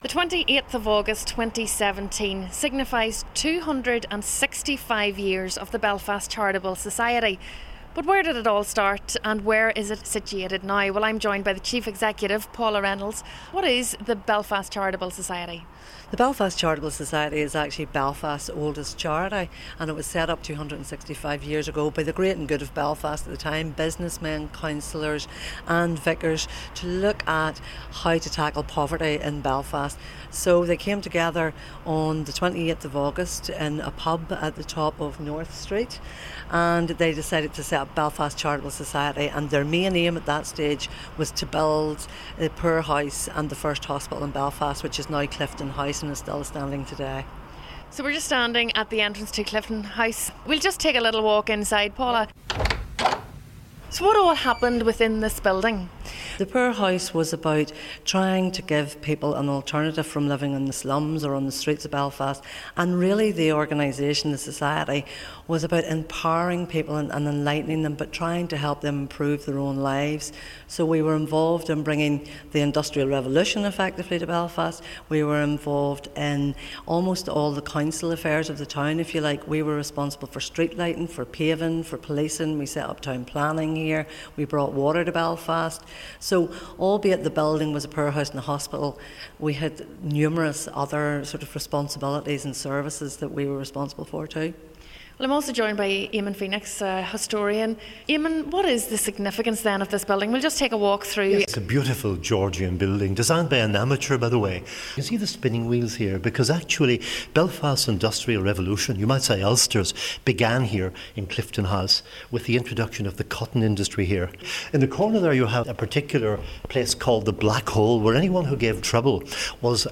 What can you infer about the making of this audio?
The Belfast Charitable Society was formed in the early 1750s to help the city's needy. It has stood the test of time and is still operating today. I visited its Clifton House headquarters.